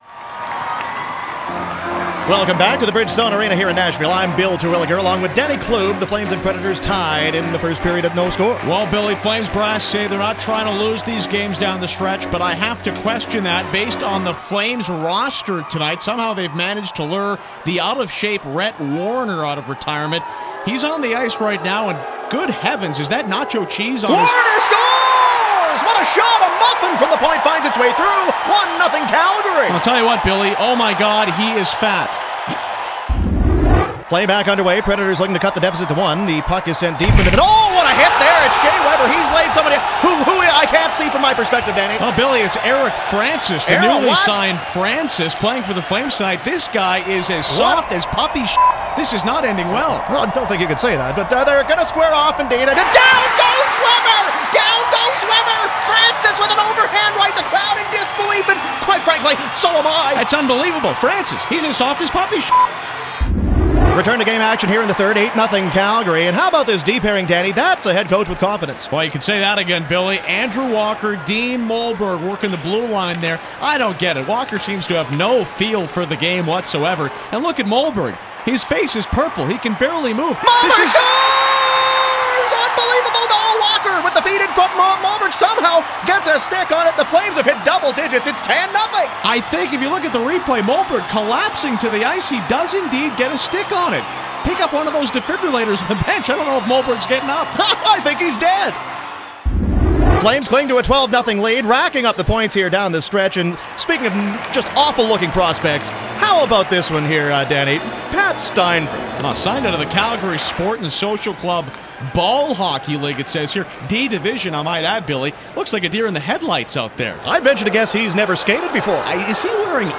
It was a silly skit where the Flames bring Warrener back.